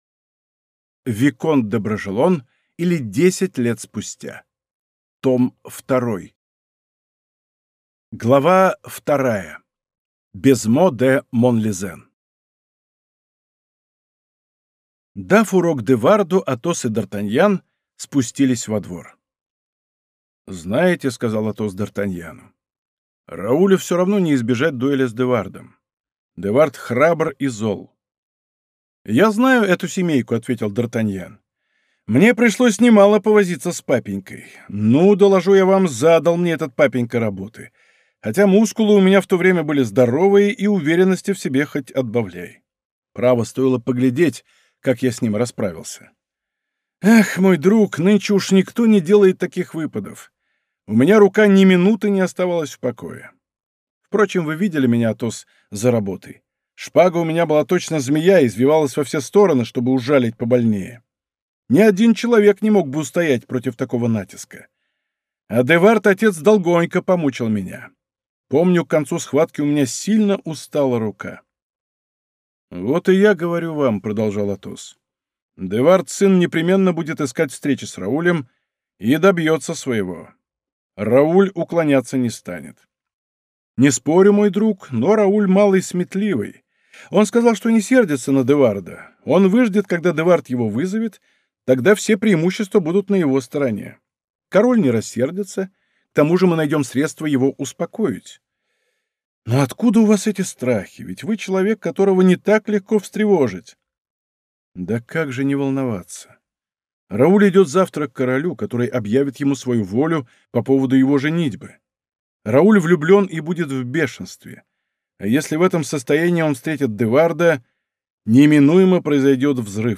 Аудиокнига Виконт де Бражелон, или Десять лет спустя. Том 2 | Библиотека аудиокниг